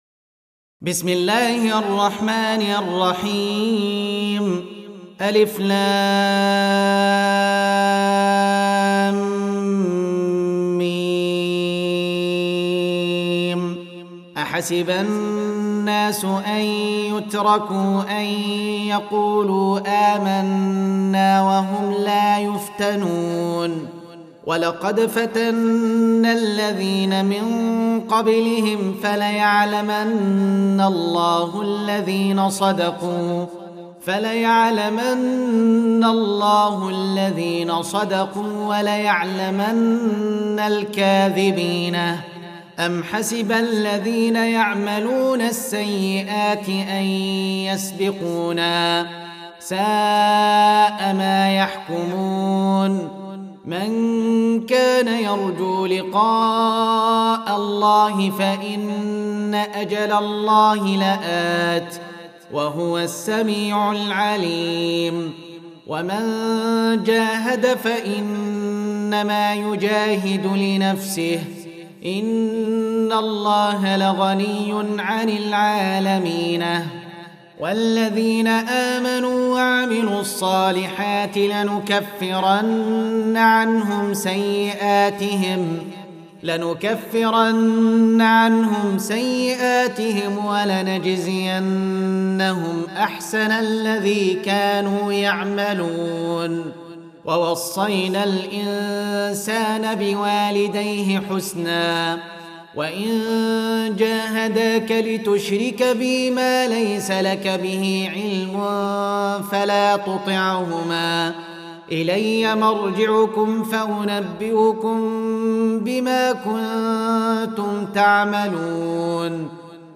Surah Repeating تكرار السورة Download Surah حمّل السورة Reciting Murattalah Audio for 29. Surah Al-'Ankab�t سورة العنكبوت N.B *Surah Includes Al-Basmalah Reciters Sequents تتابع التلاوات Reciters Repeats تكرار التلاوات